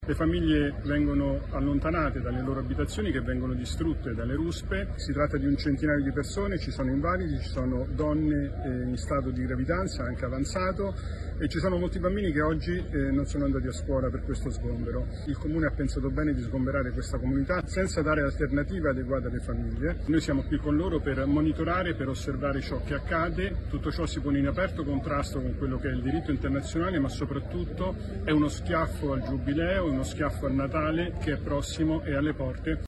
A Napoli un progetto che utilizza lo sport per promuovere l’inclusione. Il servizio